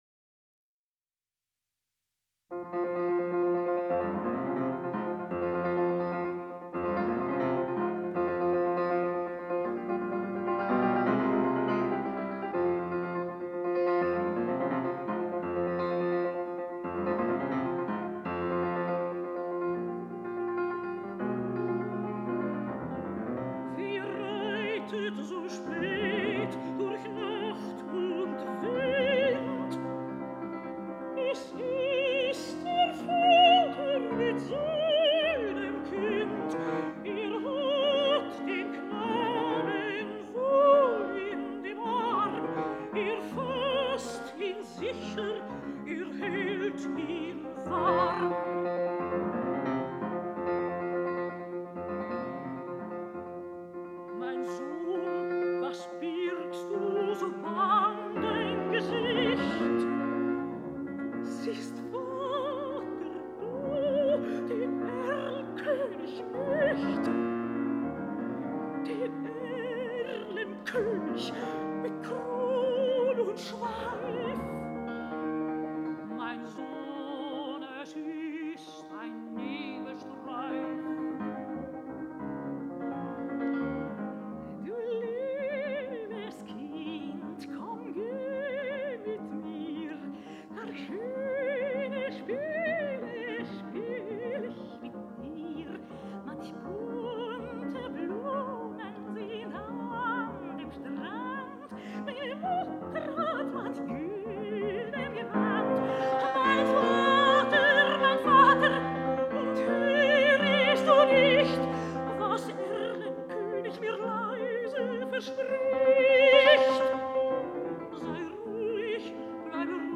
Легкий, изумительно красивого, серебристого тембра голос Шварцкопф обладал удивительной способностью перекрывать любую толщу оркестровых масс.
Певица Элизабет Шварцкопф, обладательница одного из самых выдающихся сопрано XX века, скончалась в своем доме в Австрии на 91-м году жизни.